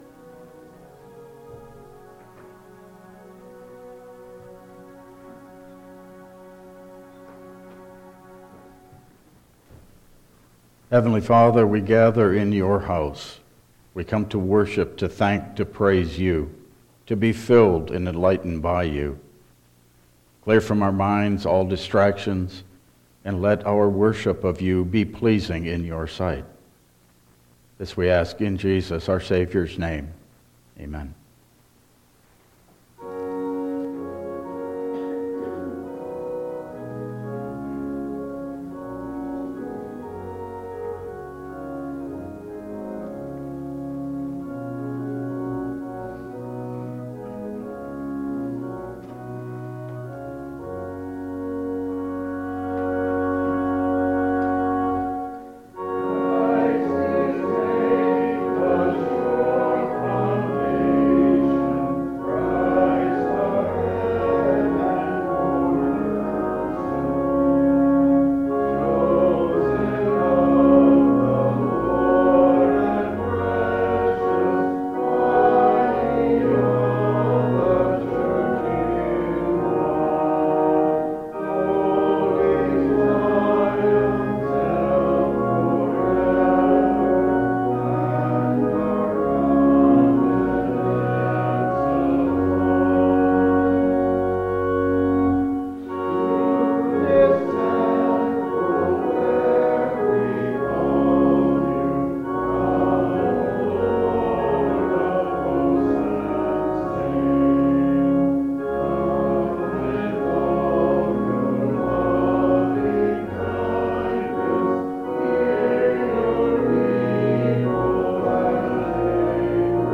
Download Files Printed Sermon and Bulletin